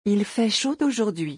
Pronunciation : eel feh shoh oh-zhoor-dwee